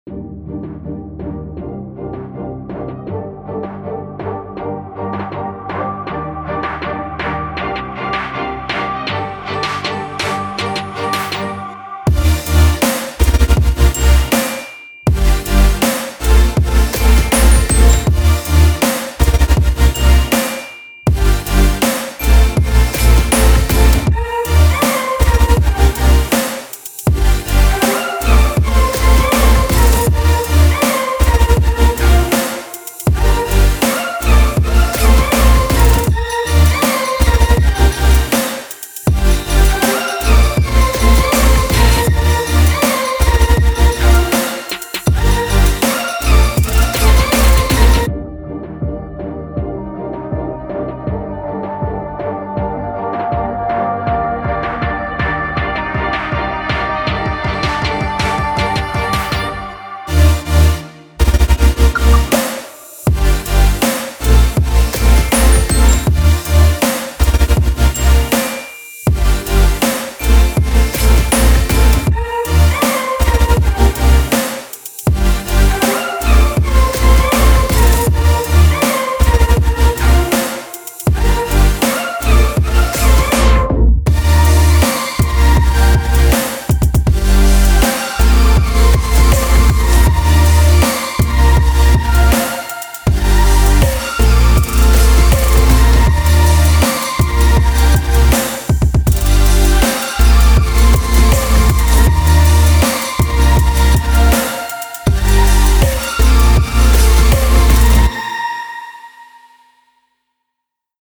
FUTURE BASS MOOD
Energetic / Inspiring / Sports / Dynamic